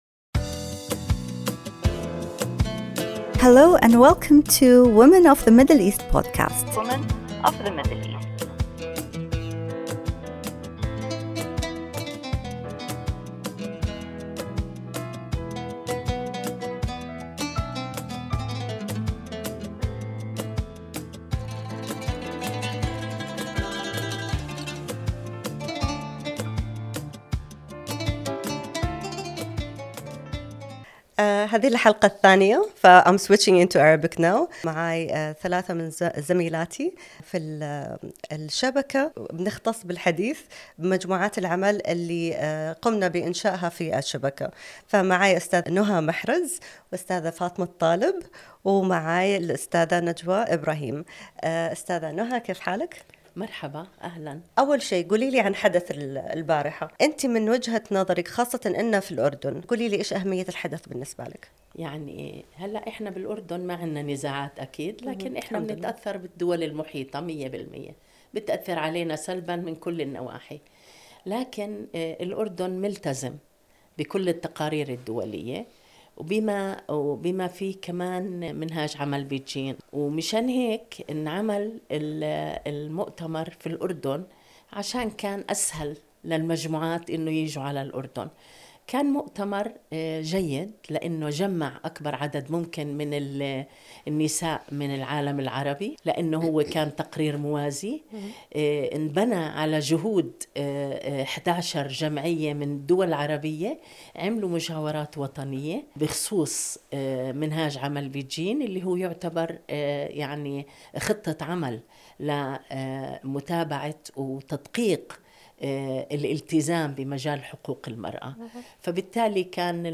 A momentous conversation in this special podcast episode! Tune in as Arab States Civil Society Organizations and feminist network members come together to reflect on the launch of the parallel regional report assessing progress on the Beijing Declaration and Platform for Action +30 in the Arab region. In this insightful discussion, we delve into the strides made over the past few decades, the challenges still faced, and the collective vision for a more inclusive and equitable future.